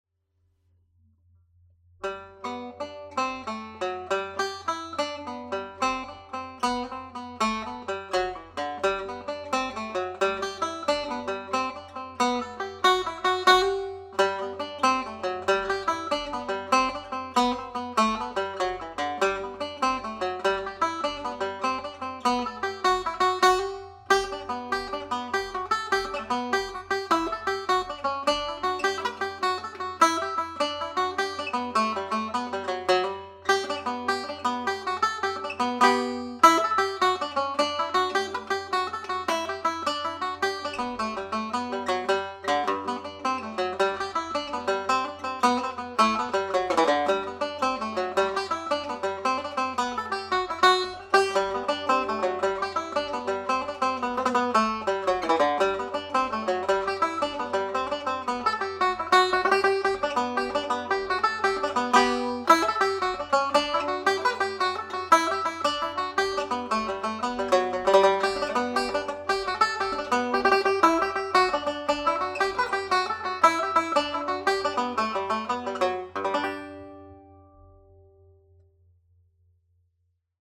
Jig (G major)
played at jig speed